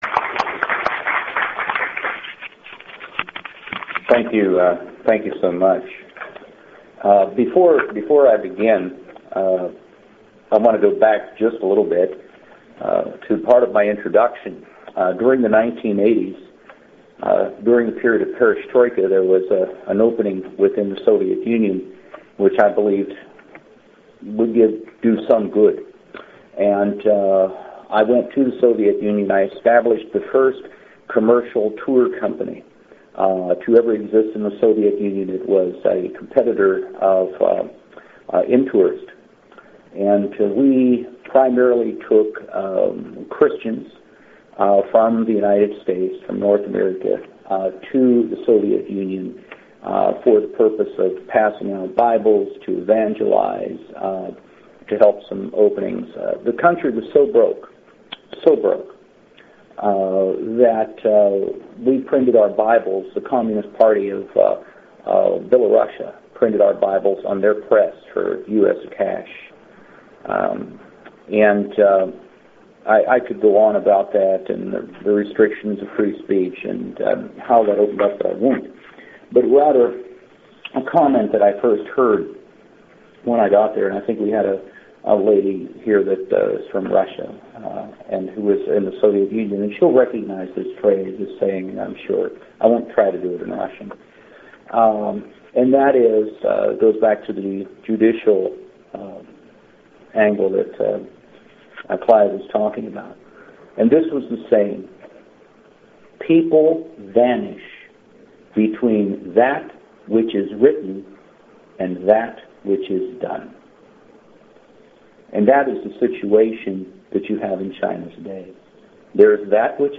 Press Club, New York City, New York